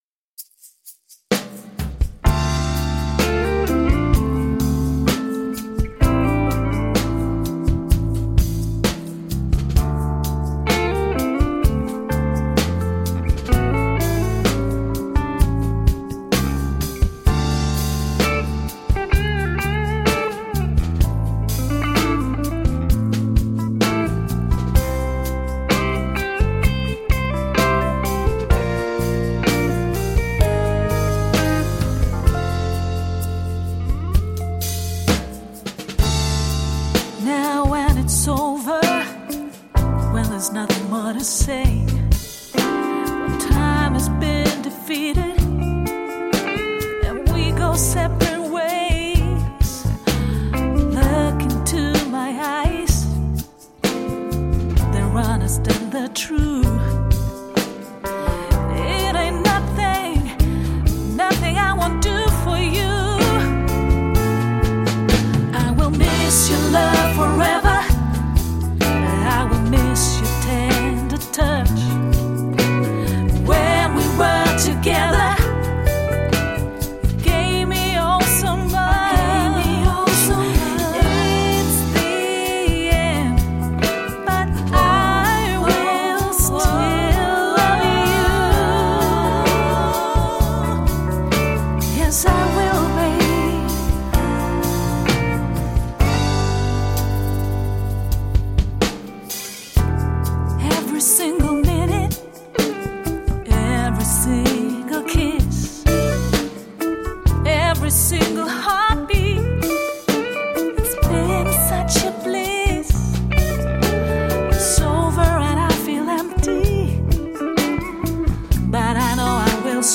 Funny, funky blues.
Tagged as: Alt Rock, Funk, Blues, Ironic Rock